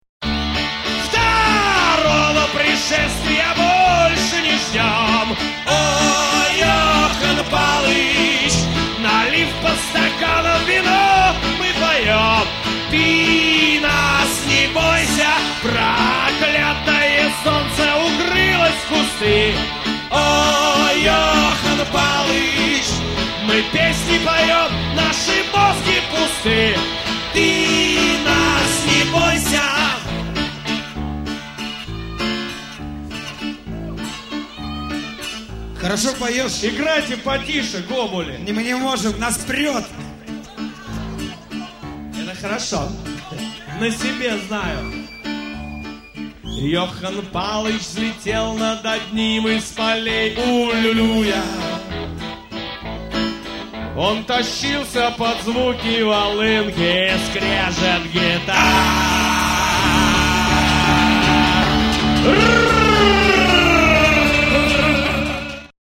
Шаболовка (1994)
фрагмент песни ( 1 мин.)
AUDIO, stereo